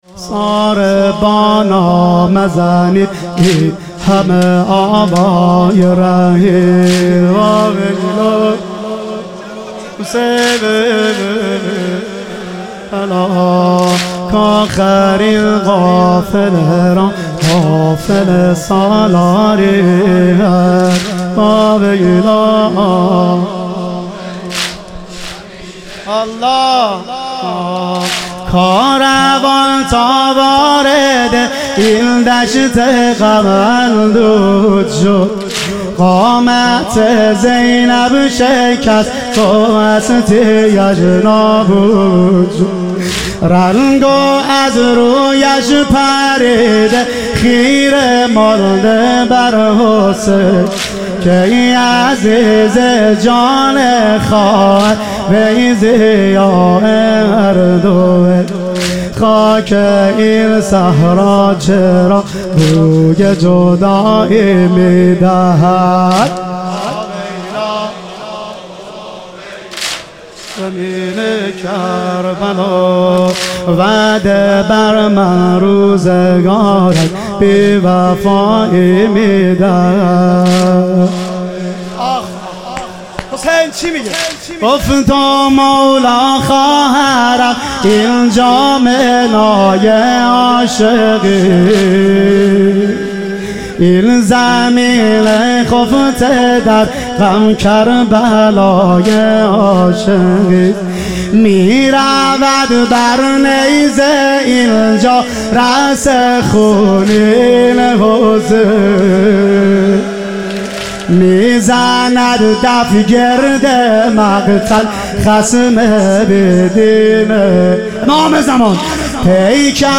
محرم الحرام - واحد